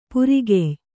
♪ purigey